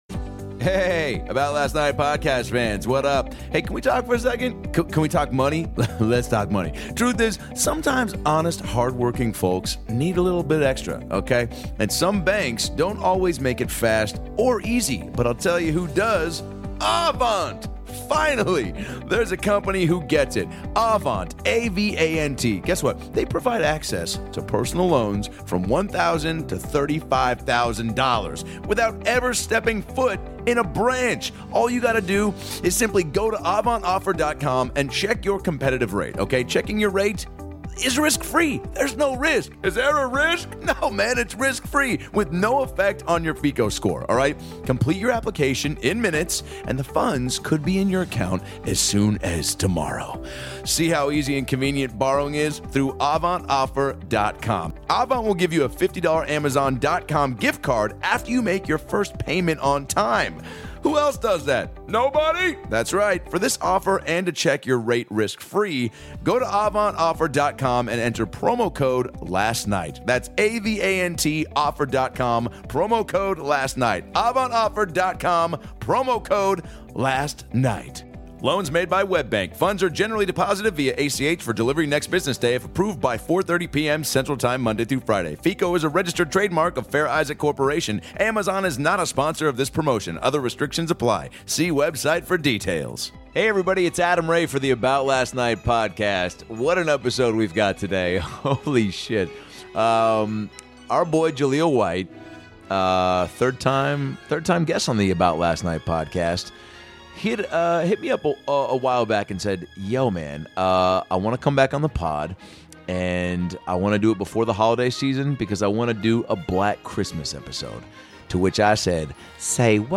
Jaleel White returns and brings hilarious comedian Guy Torry with him to give us a break down of the "black Xmas!" We haven't laughed this hard in a long time. From the food, to the music, the unique traditions, heartfelt family stories, and the worst gifts of all time, these guys were a dynamic duo. Plus an amazing Family Matters story you won't hear anywhere else.